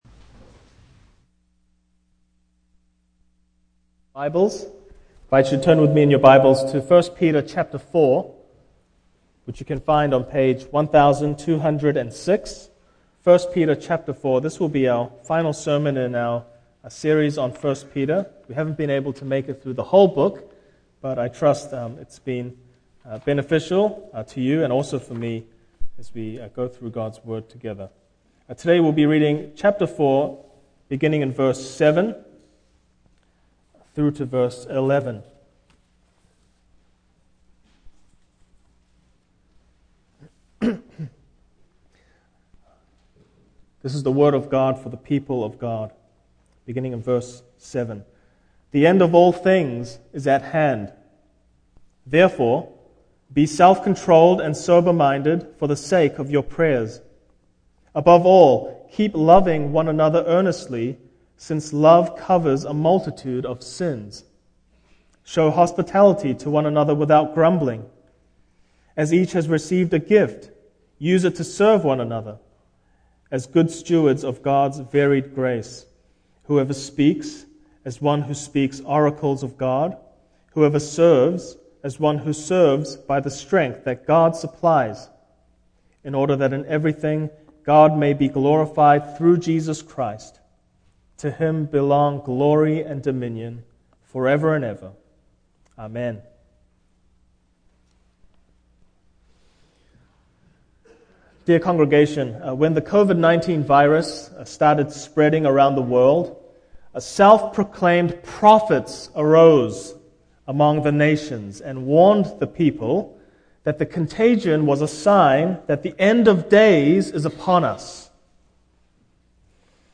Life Together in Light of the End (end of sermon clipped off due to technical difficulties)
Passage: 1 Peter 4:7-11 Service Type: Morning